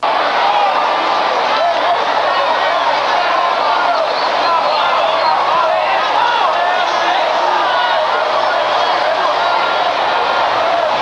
Crowded Football Game Sound Effect
Download a high-quality crowded football game sound effect.
crowded-football-game.mp3